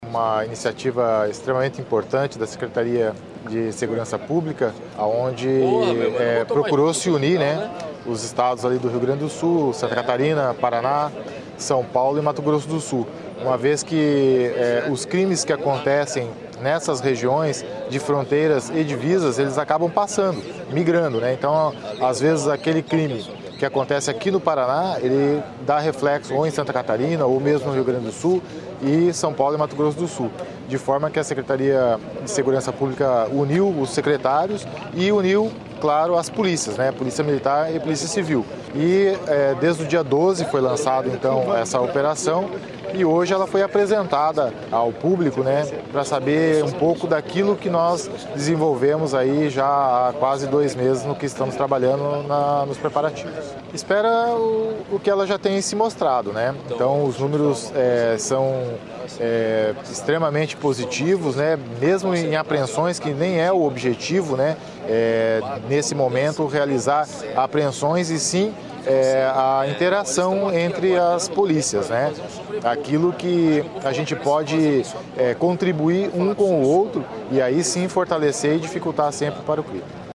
Sonora do comendante-geral da PMPR, coronel Sérgio Almir Teixeira, sobre o lançamento da operação Fronteiras e Divisas Integradas